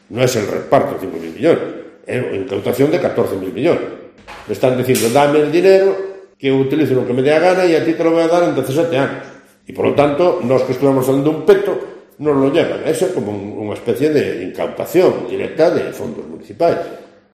Declaraciones del alcalde de Pontevedra, Miguel Anxo Fernández Lores, tras reunirse con 10 alcaldes de diferentes colores políticos, incluido el popular Almeida.